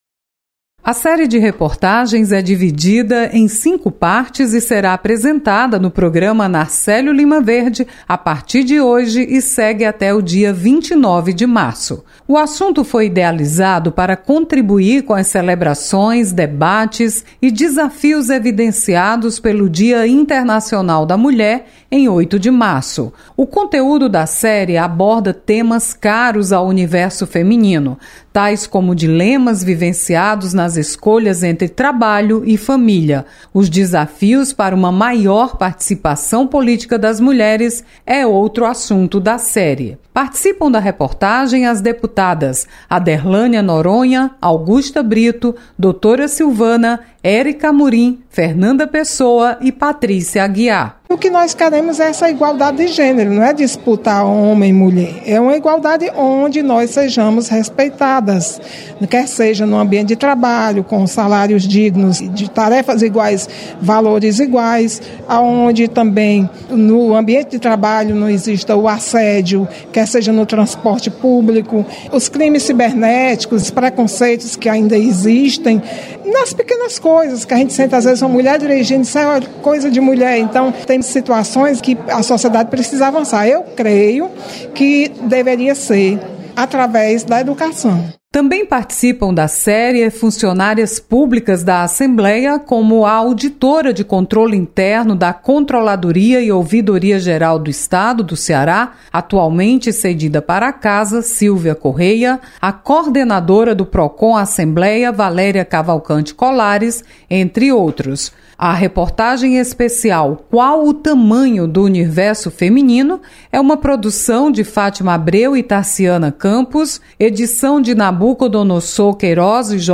Rádio FM Assembleia apresenta Reportagem Especial com o tema Qual o tamanho do universo feminino?